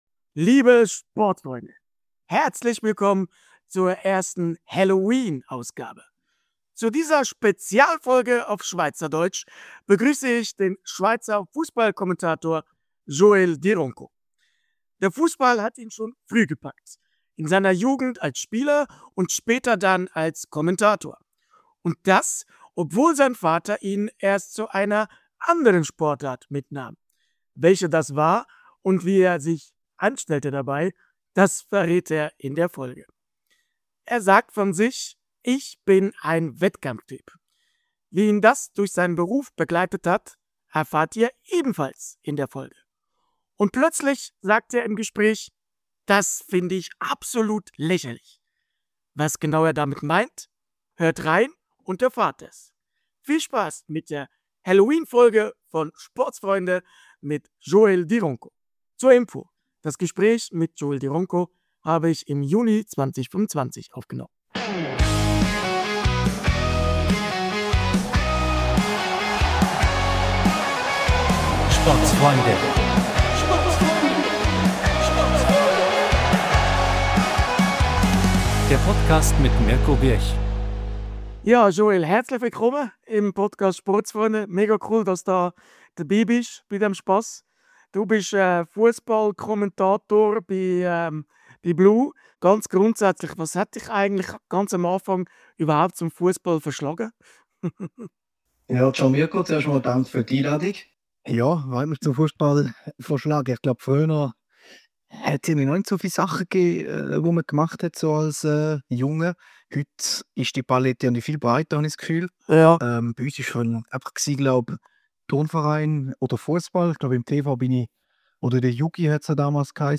(Info: Gesprächsaufnahme: Juni 2025) Copyright Intro & Outro Melodie: ...